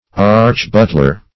Search Result for " archbutler" : The Collaborative International Dictionary of English v.0.48: Archbutler \Arch`but"ler\, n. [Pref. arch- + butler.] A chief butler; -- an officer of the German empire.